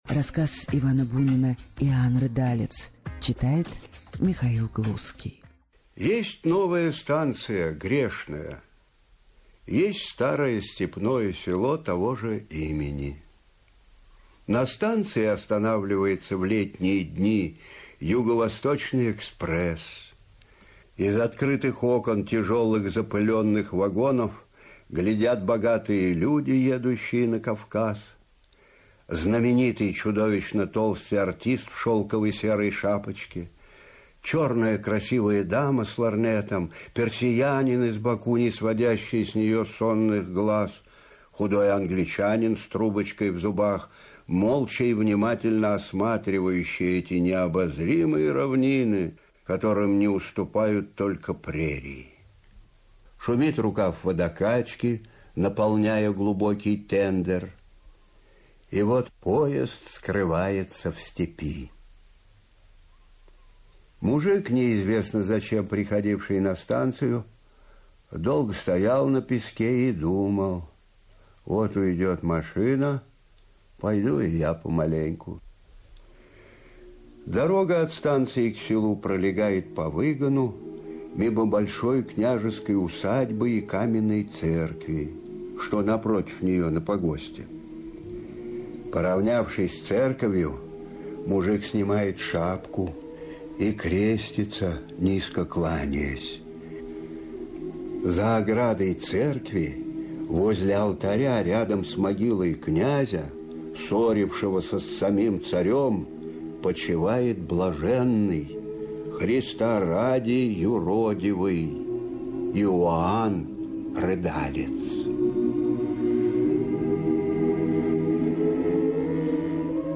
Иоанн Рыдалец Чтение рассказа может быть заменено прослушиванием его аудиозаписи с сайта «Старое радио» в исполнении М. Глузского.